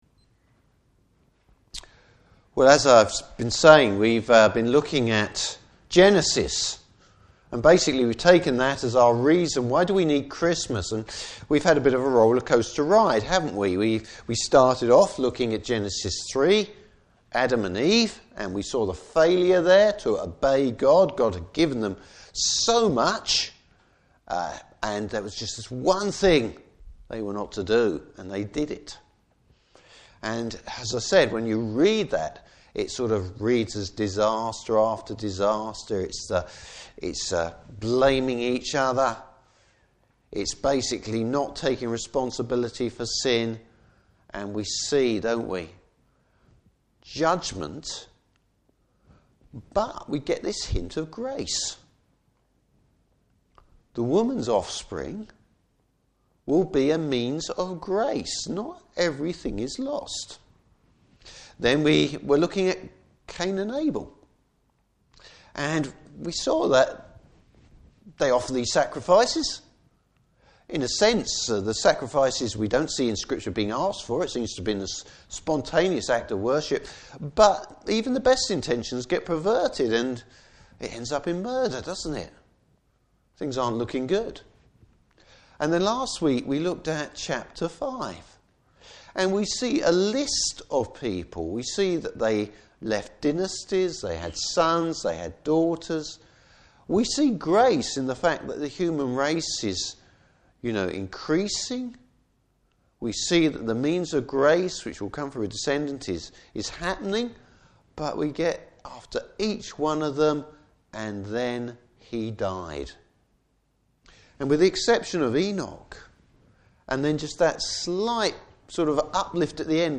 Service Type: Morning Service Bible Text: Genesis 9:18-29.